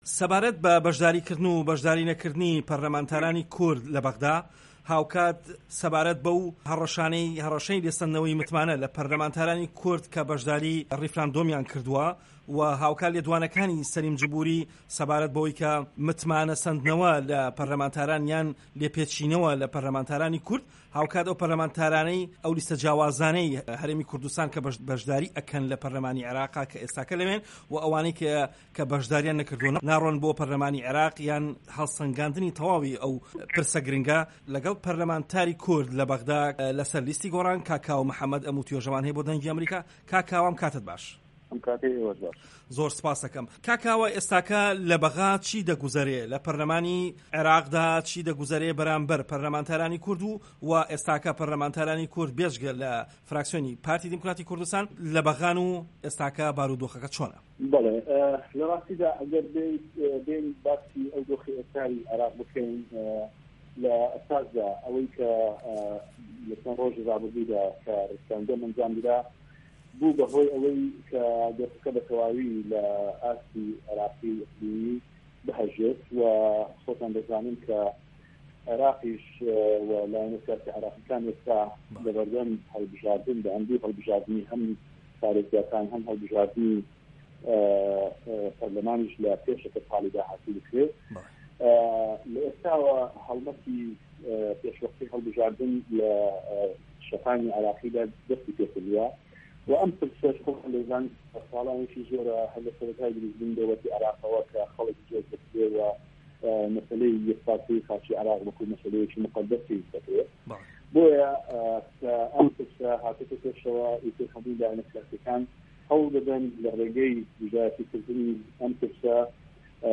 Interview with Kawa Mohammad